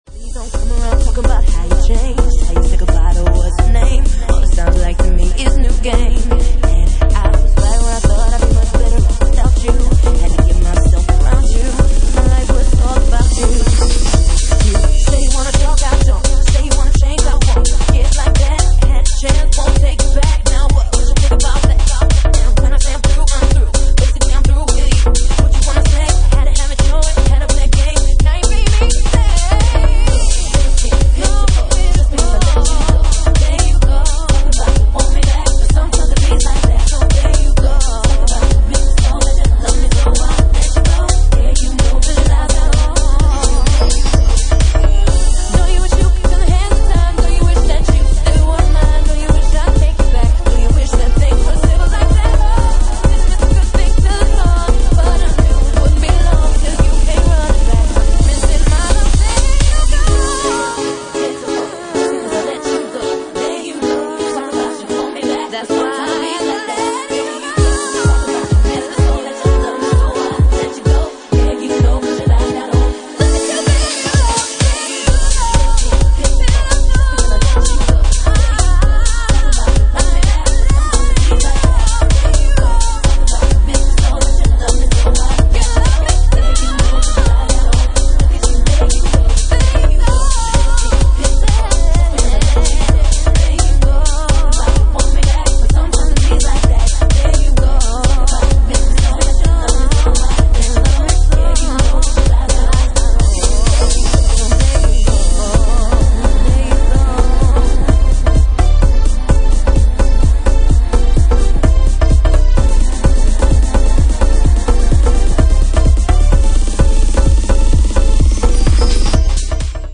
Genre:Jacking House